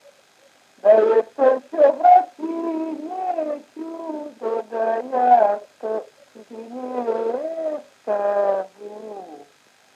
Мягкое цоканье (совпадение литературных аффрикат /ц/ и /ч’/ в мягком /ц’/)
/да-э-то-вс’о” бра”-цыы н’ее-ц’у”-до да-йаа”-то ц’уу-дн’е” ска-жу:”/